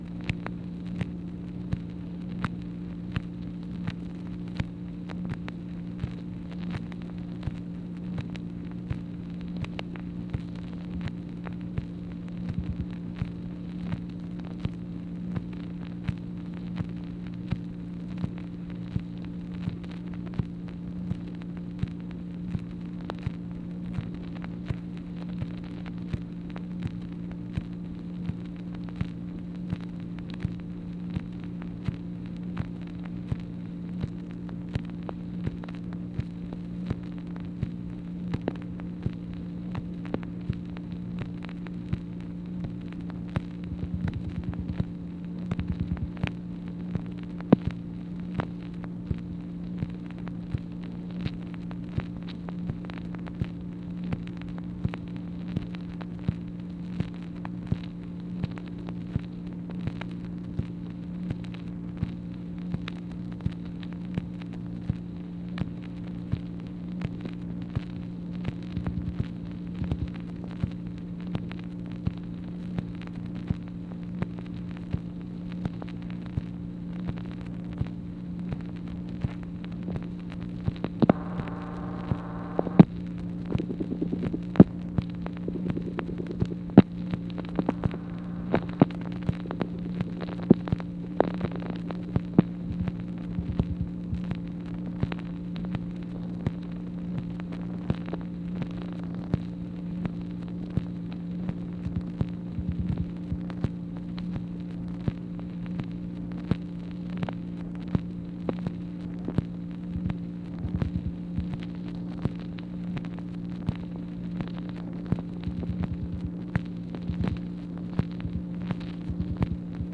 MACHINE NOISE, April 13, 1964
Secret White House Tapes | Lyndon B. Johnson Presidency